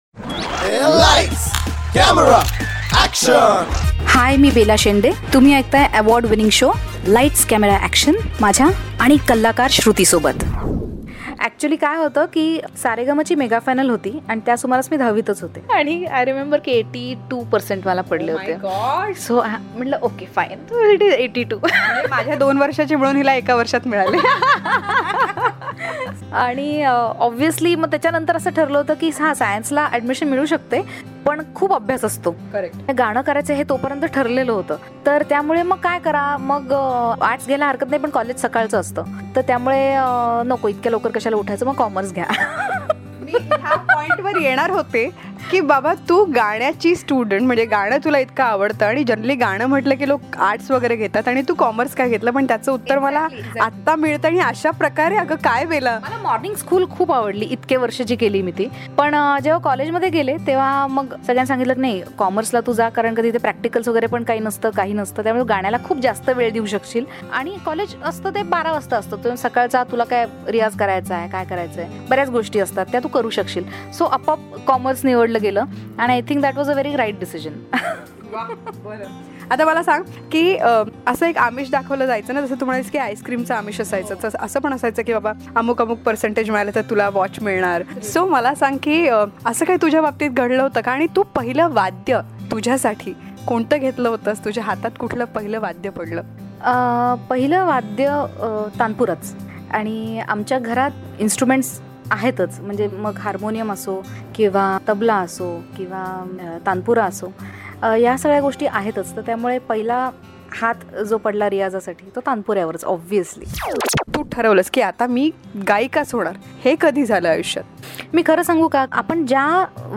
CONVERSATION WITH SINGER BELA SHENDE